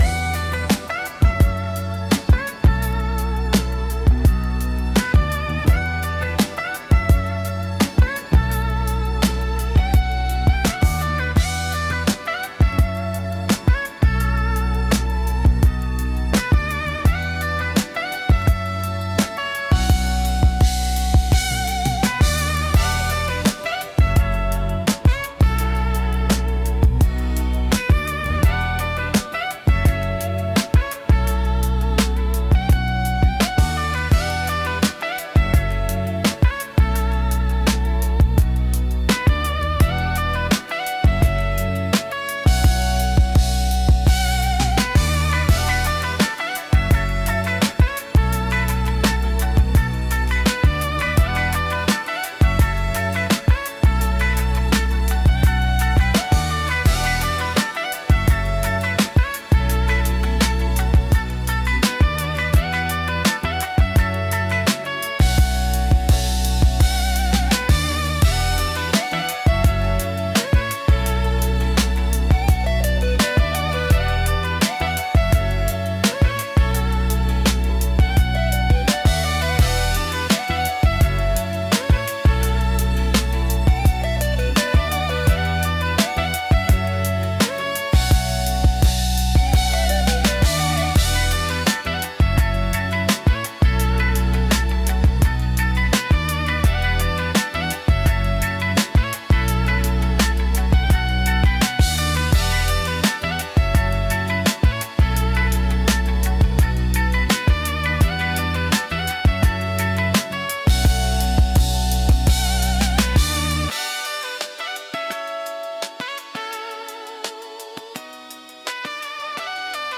リズムの重厚感とグルーヴ感、感情豊かなボーカルが特徴で、深みと熱量のあるサウンドが魅力です。